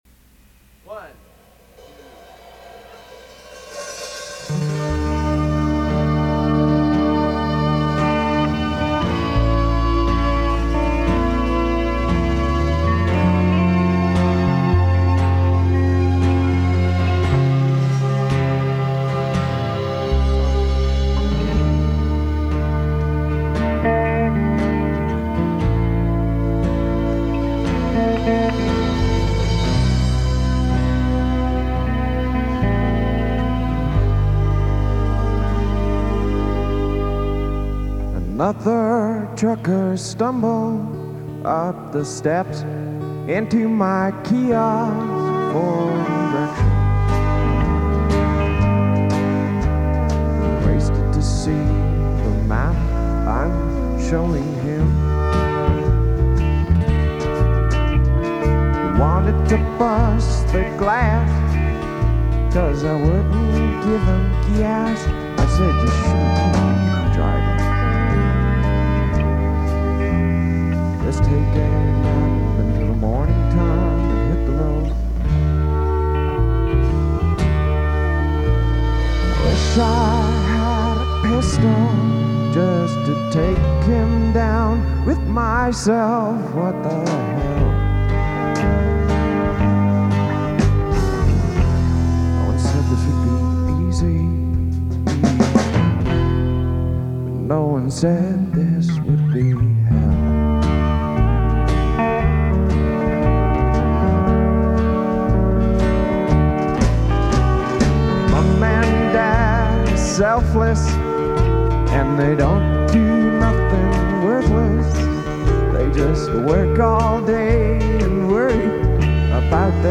Source : Cassette -> MP3/WAV/FLAC
compression/limiting/EQ added